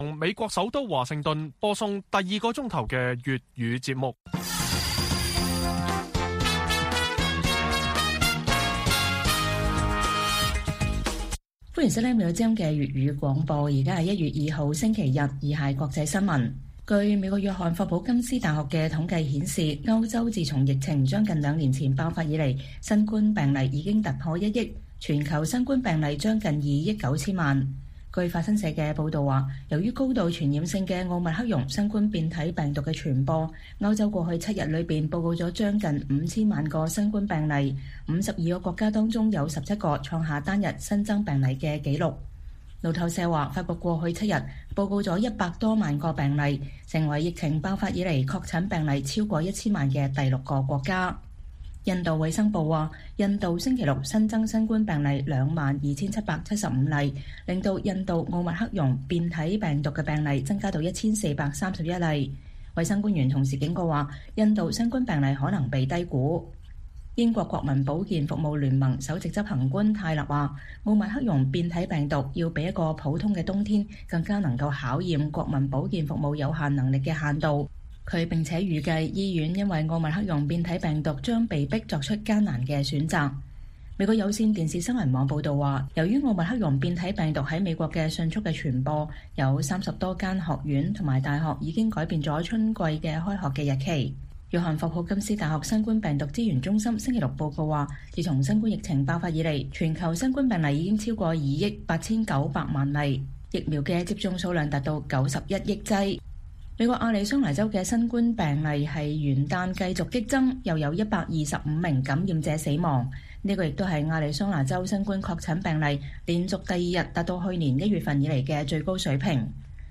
粵語新聞 晚上10-11點：中國歡呼RCEP貿易協定生效 華盛頓壓力徒升